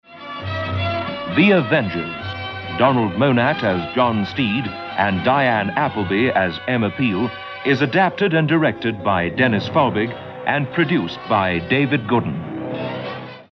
You know you're listening to a Springbok Radio programme by the distinctive voices of the announcers that can be heard at the top and tail of each episode...
This announcement was usually heard just once a week, ordinarily at the end of the Friday night transmission.